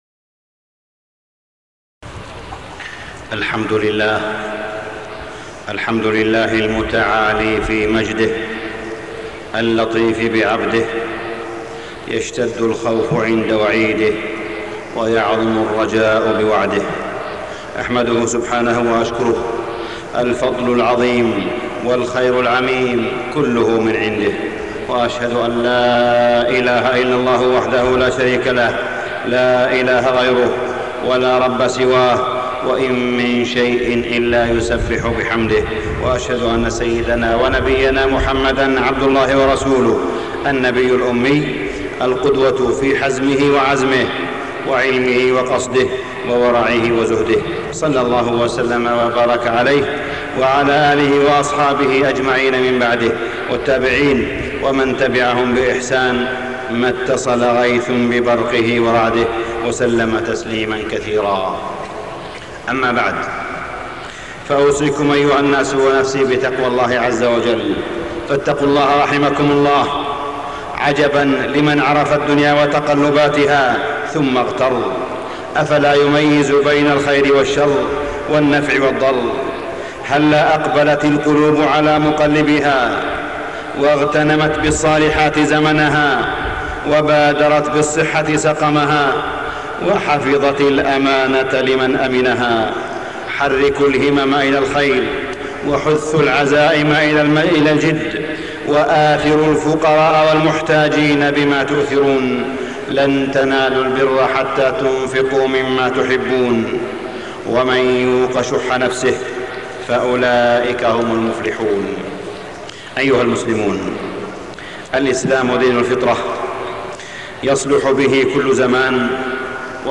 تاريخ النشر ٨ صفر ١٤٢٦ هـ المكان: المسجد الحرام الشيخ: معالي الشيخ أ.د. صالح بن عبدالله بن حميد معالي الشيخ أ.د. صالح بن عبدالله بن حميد الإسلام دين الفطرة